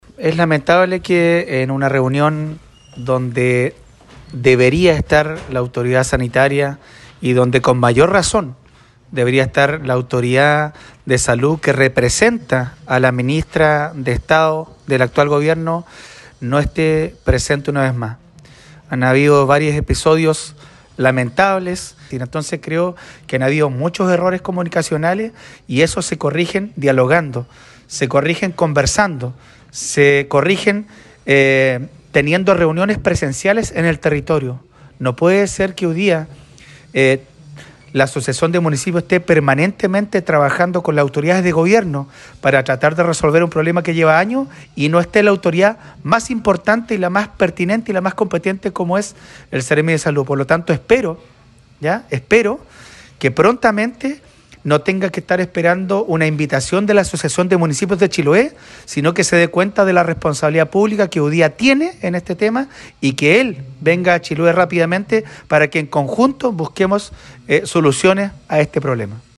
Sin embargo, pese a estas temáticas, la reunión nuevamente estuvo marcada por la ausencia del seremi de Salud Carlos Becerra, situación que fue apuntada por el alcalde de Quéilen, Marcos Vargas.
03-ALCALDE-MARCOS-VARGAS.mp3